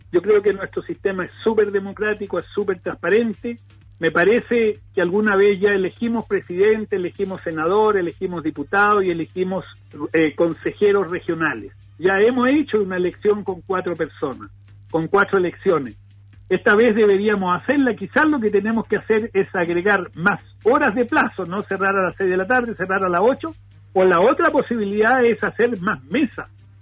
El actual Consejero Regional por Los Lagos y candidato a Gobernador Regional por Renovación Nacional, Ricardo Kuschel, manifestó en Radio Sago su posición a favor de mantener un día de elección.